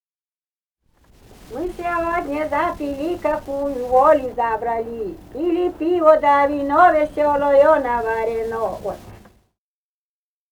«Мы сегодня запили» (частушка).